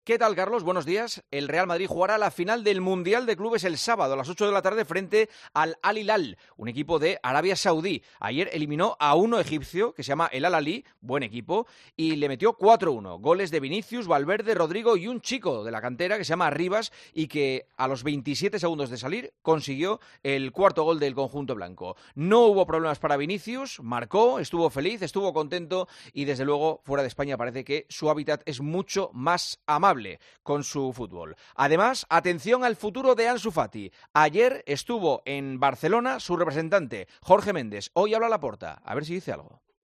El comentario de Juanma Castaño
El presentador de 'El Partidazo de COPE' analiza la actualidad deportiva en 'Herrera en COPE'